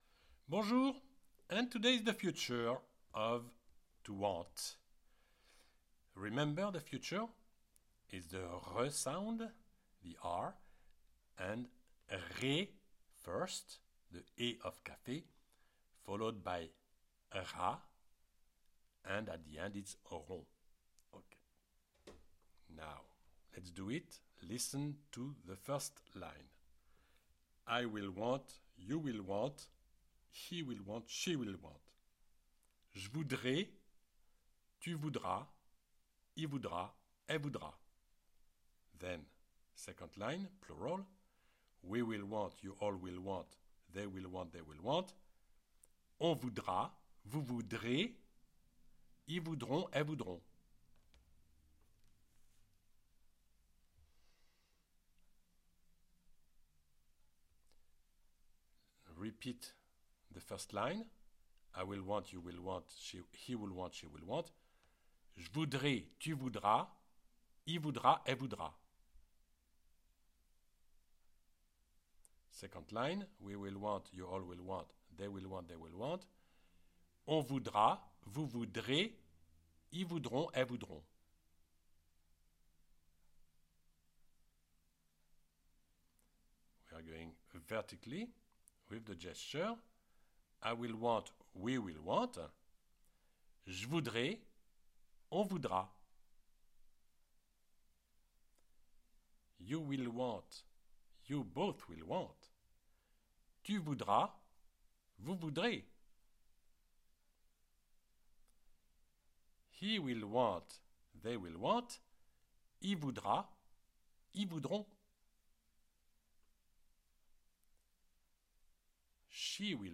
CLICK ON THE PLAY BUTTON BELOW TO PRACTICE ‘VOULOIR’, ‘TO WANT’, IN THE FUTURE TENSE Just the sight of the classic french conjugation of vouloir Chart makes it difficult to learn the French conjugation .
You read and you repeat with the audio.